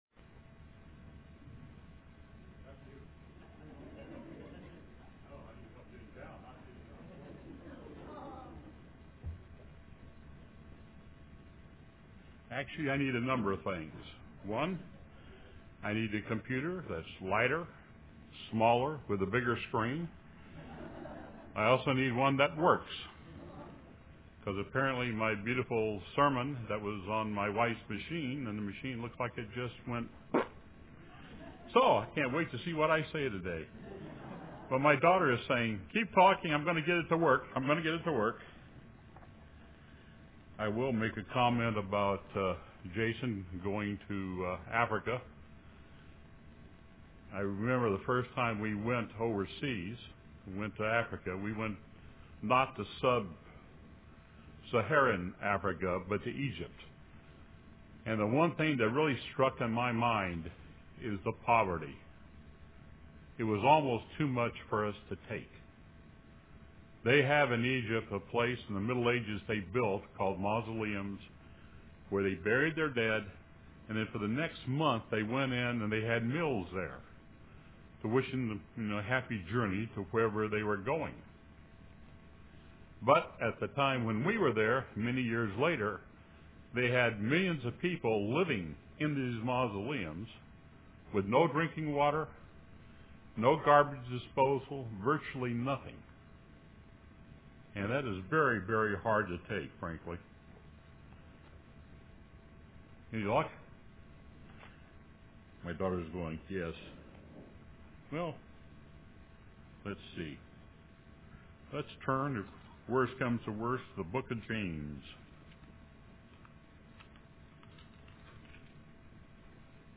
UCG Sermon Notes A Partial List of Scriptures: Jas 1:1 James, a servant of God and of the Lord Jesus Christ, to the twelve tribes which are scattered abroad, greeting.